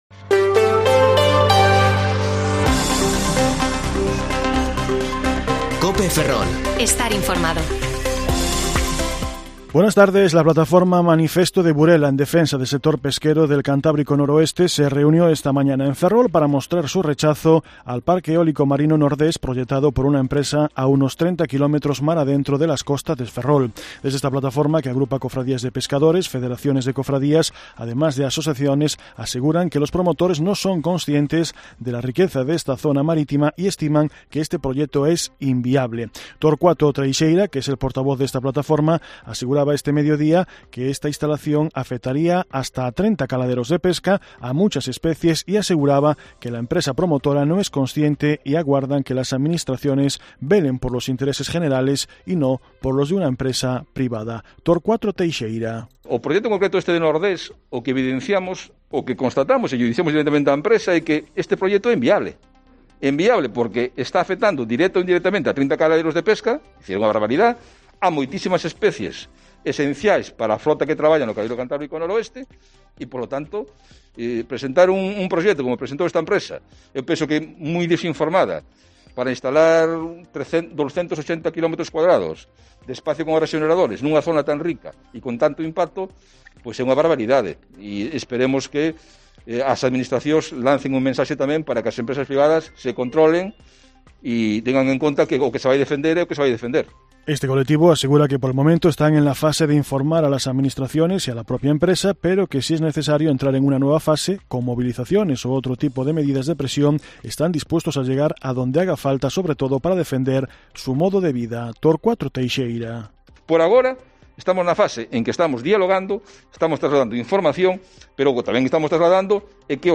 Informativo Mediodía COPE Ferrol 17/2/2022 (De 14,20 a 14,30 horas)